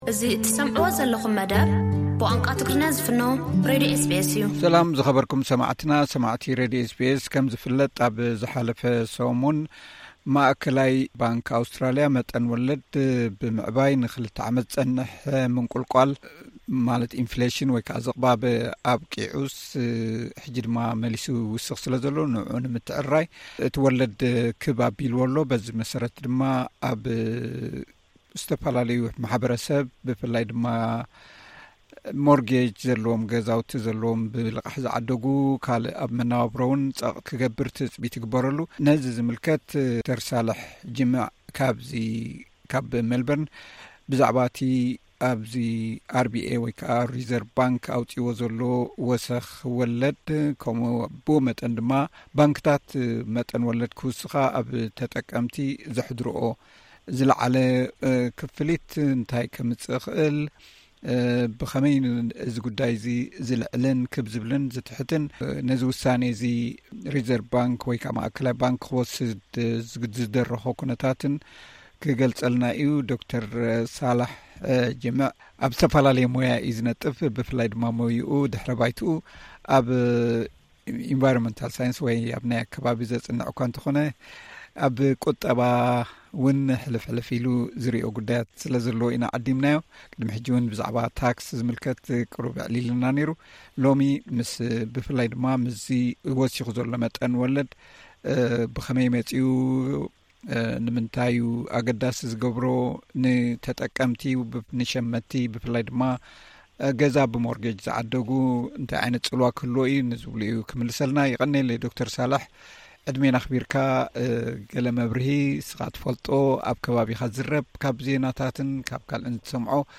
ዝርርብ